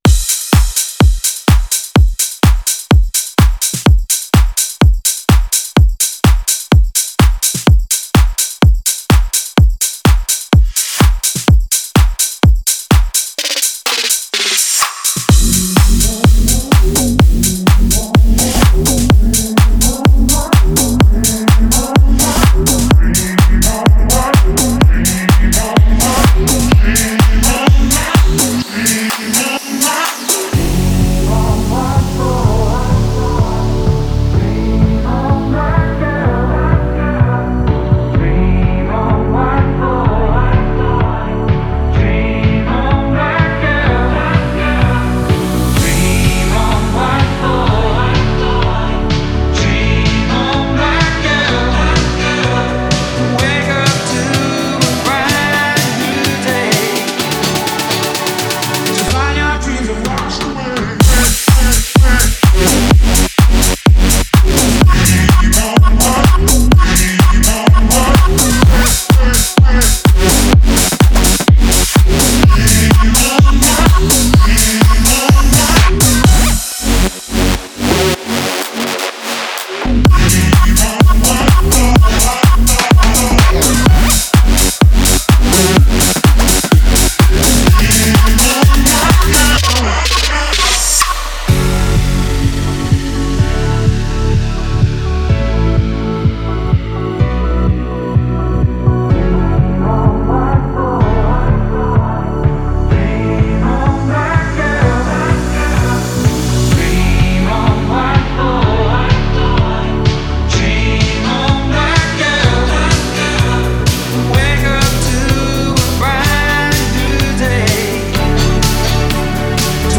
это энергичный трек в жанре электро-хаус